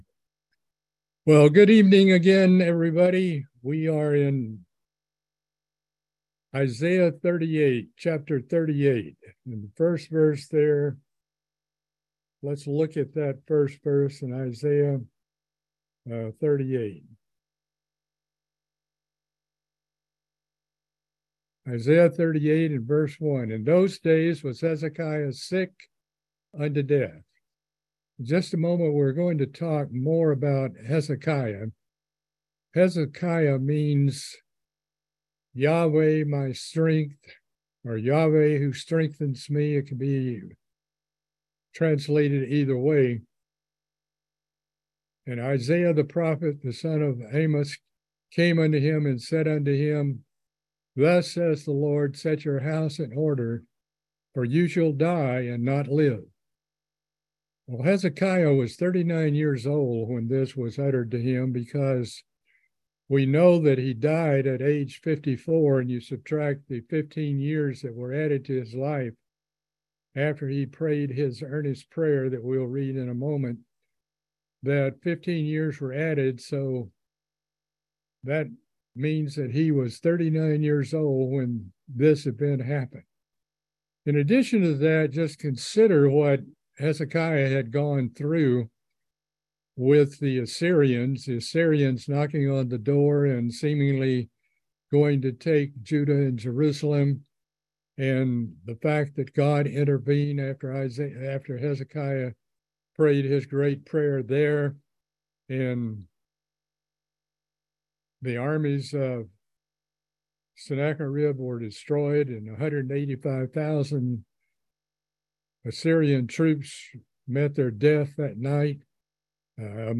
Book of Isaiah Bible Study - Part 26